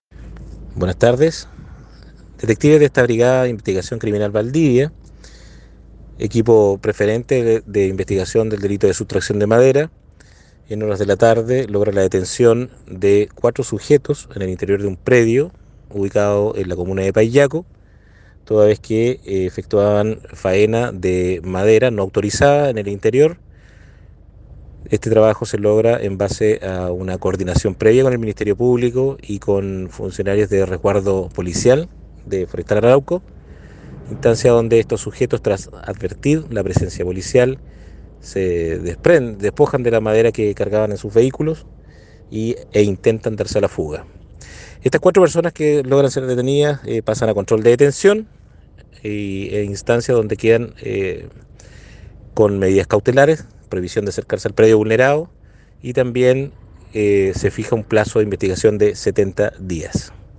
cuña